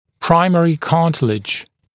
[‘praɪmərɪ ‘kɑːtɪlɪʤ][‘праймэри ‘ка:тилидж]первичный хрящ